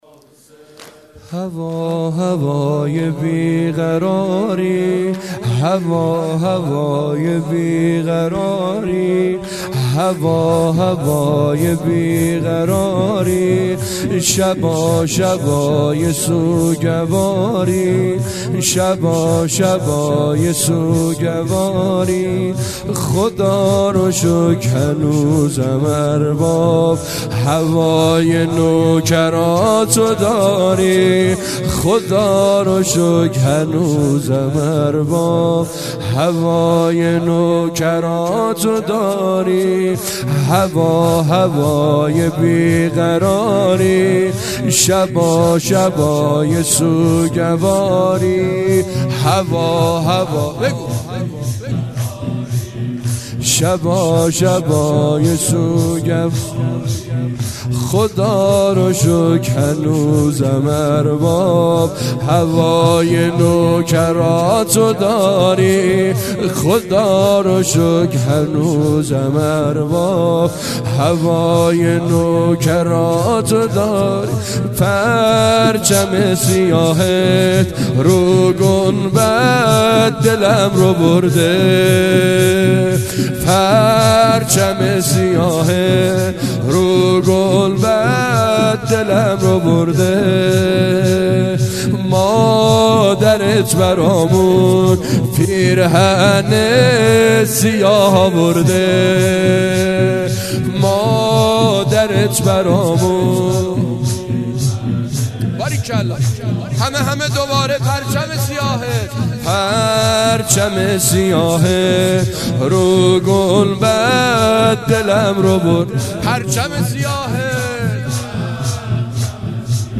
زمینه-شب اول محرم-96